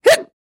Звуки икоты
Женщина громко икнула